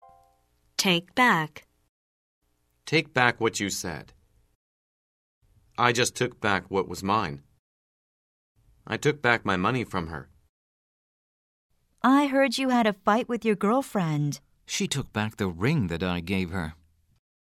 通过生动的对话说明短语的实际表达用法，8000多句最实用的经典表达，保证让你讲出一口流利又通顺的英语，和老外聊天时再也不用担心自己的英语错误百出了！